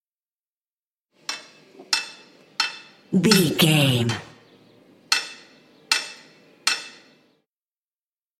Construction ambience hit metal single
Sound Effects
urban
ambience